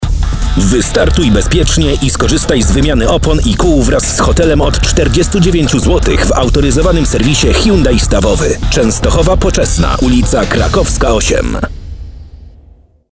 Barwny głos, malujący dźwiękami obrazy, które zaskakują i fascynują słuchacza.
Demo głosowe